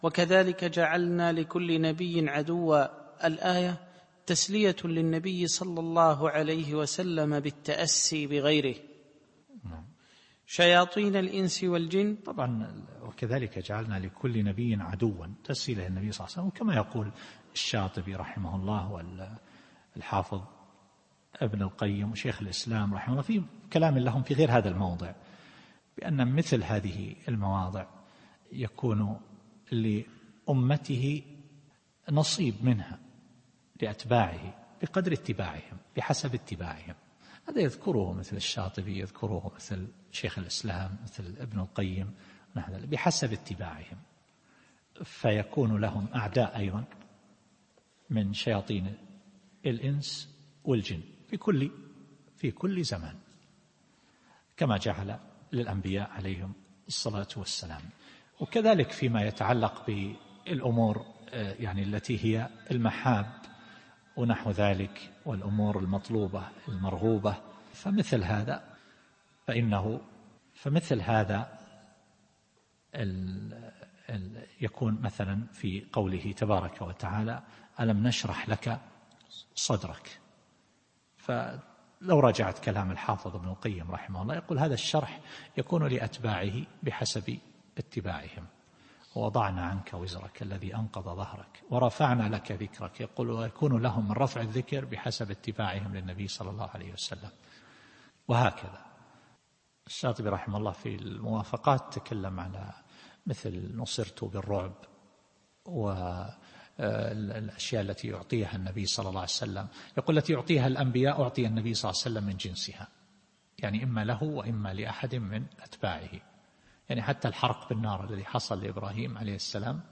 التفسير الصوتي [الأنعام / 112]